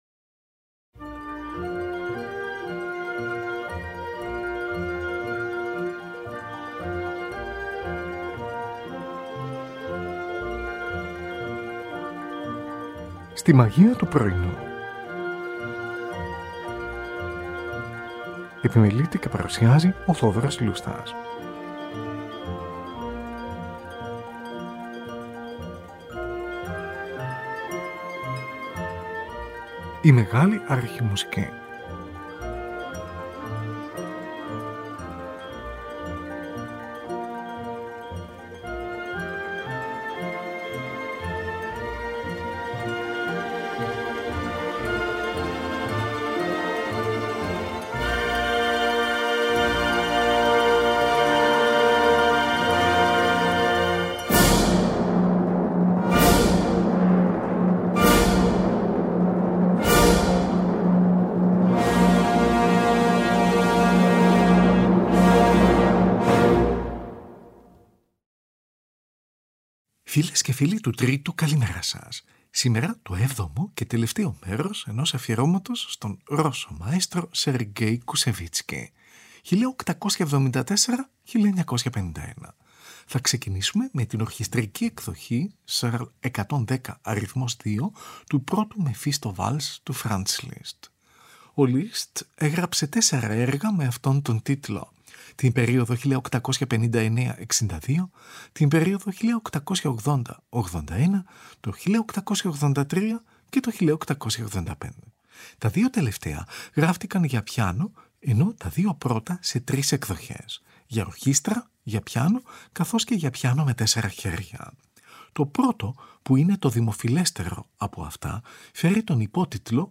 ορχηστρική εκδοχή
για μεγάλη ορχήστρα